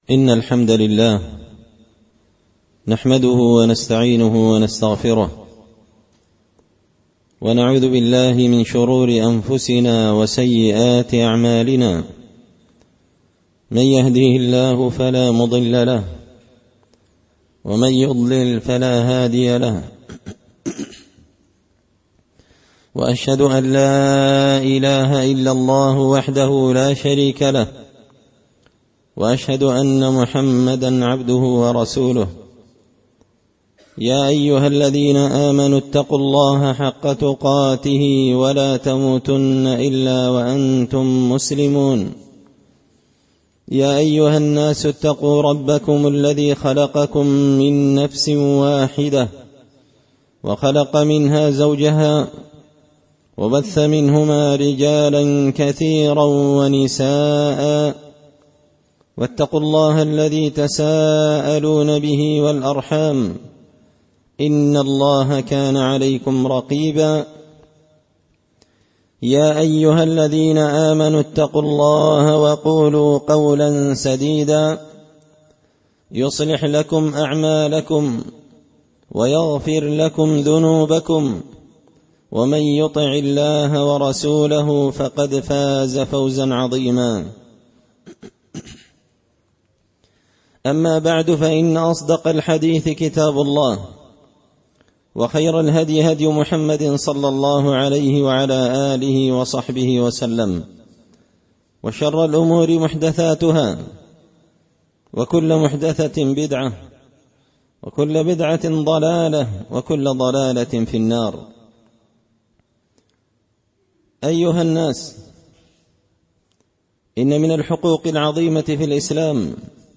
خطبة جمعة بعنوان – حق الجار
دار الحديث بمسجد الفرقان ـ قشن ـ المهرة ـ اليمن